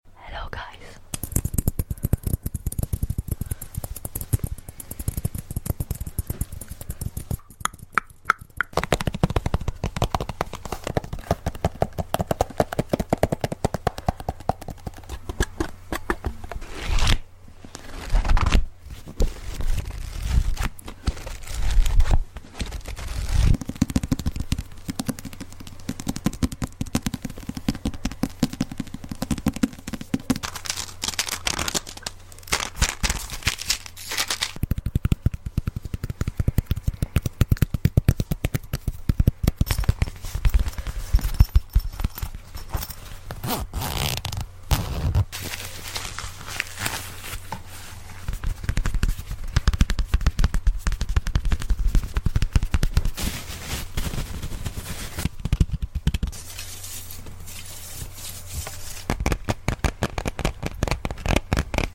ONE OF MY FIRST ASMR sound effects free download